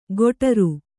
♪ goṭaru